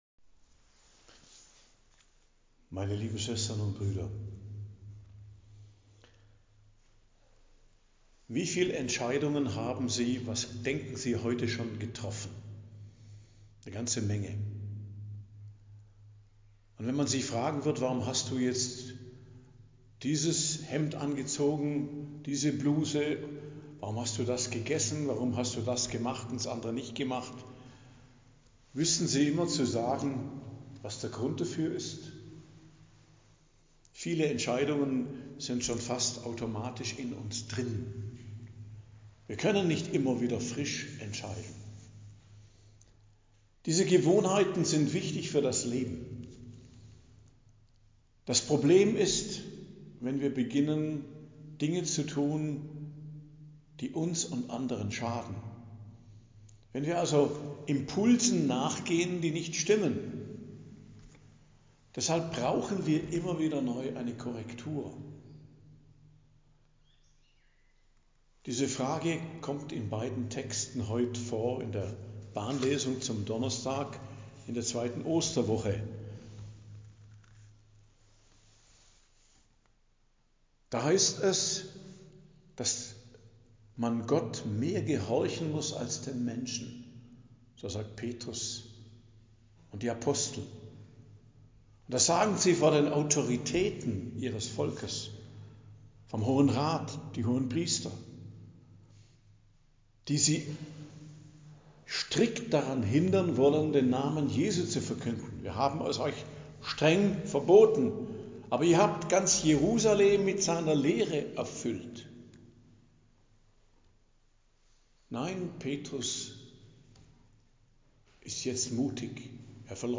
Predigt am Gedenktag Hl. Josef der Arbeiter, 1.05.2025